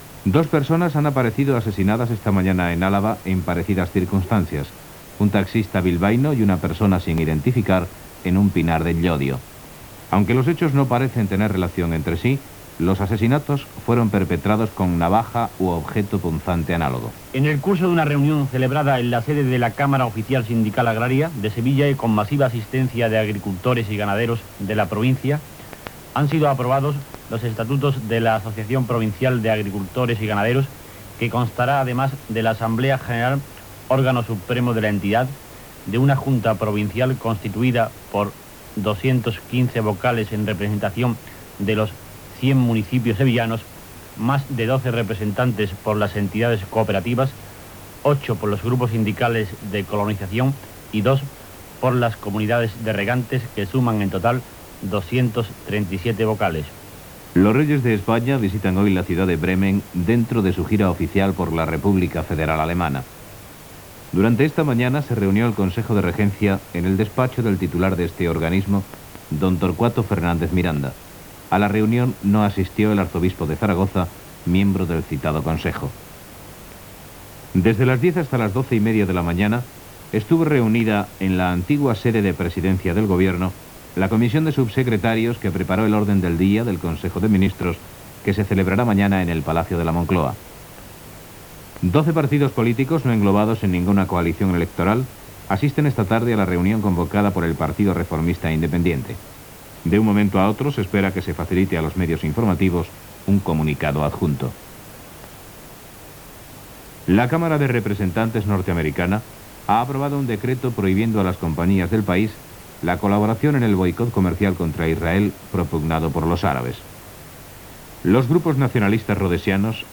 Dos asssassinats a Àlaba, visita dels reis d'Espanya a Bremen, consell de ministres, Partido Reformista Independiente, notícies internacionals. Butlletí meteorològic per a la navegació costera. Identificació i sintonia.
Informatiu